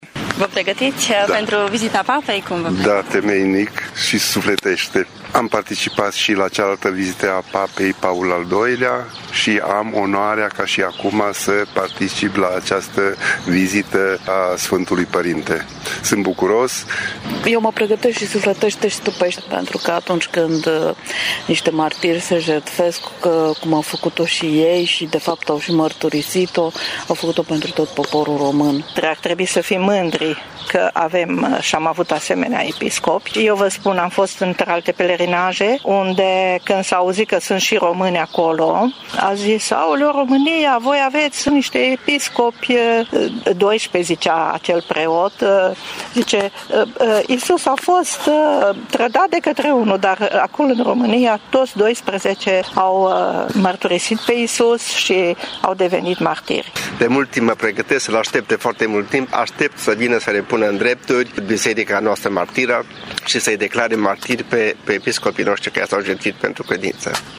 Credincioșii greco-catolici din Târgu-Mureș sunt nerăbdători să ajungă la Blaj: